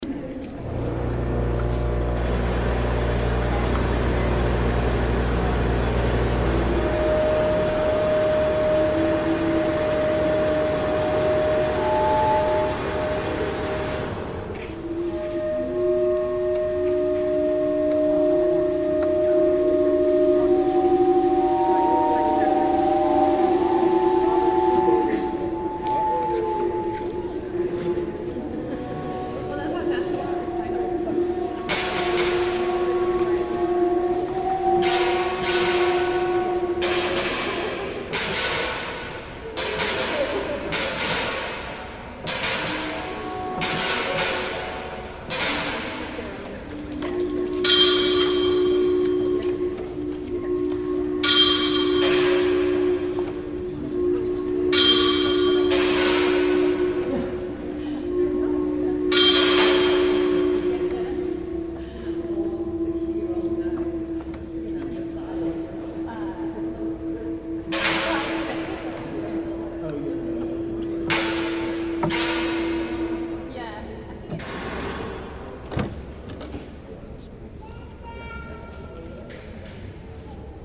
David Byrne's building, 8.8.08